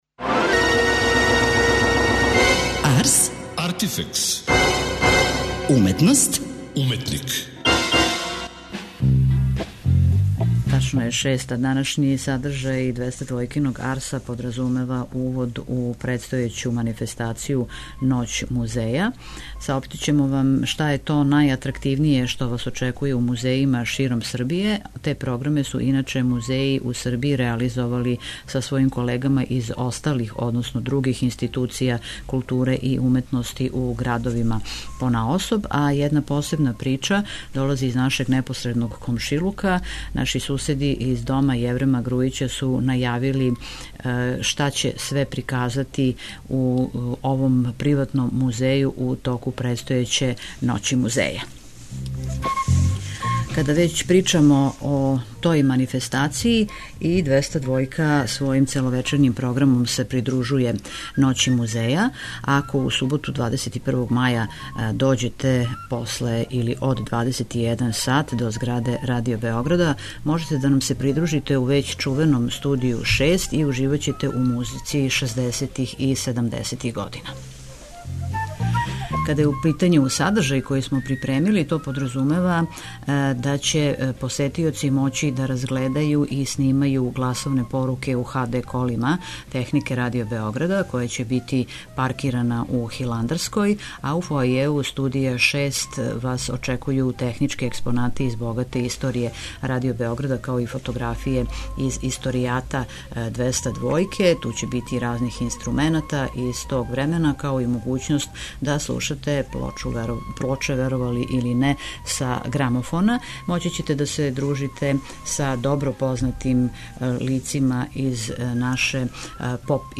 Наш репортер је био у прилици да види оно што ће посетиоци видети у Ноћи музеја: "српску Мона Лизу", портрет Краљице Наталије, ремек дело Стеве Тодоровића и један од најпознатијих портрета у српском сликарству, краљичине личне предмете и поклоне које су чланови породице Јеврема Грујића добијали од једне од најлепших српских владарки: предмете од кристала, бронзе, свиле, чипке, злата и сребра, да научи како се прави поклон краљици - краљевски реверанс и да проба омиљену чоколадну торту краљице Наталије, направљену по оригиналном рецепту из 1885. године. Доносимо вам и преглед занимљивих изложби које ће у Ноћи музеја бити одржане у градовима у Србији.